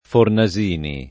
Fornasini [ forna @& ni ] cogn.